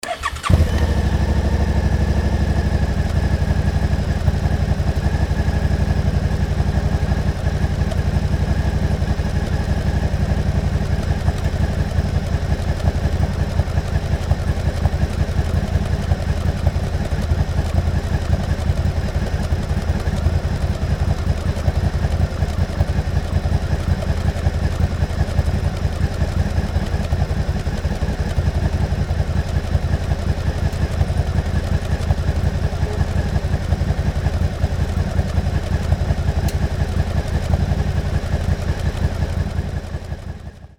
650ccのV型2気筒エンジンという事で
アイドリング時の排気音
ドコドコした不規則な鼓動感が味わえるので
あえてアイドリングのみの音を収録しました。
sv650_idling.mp3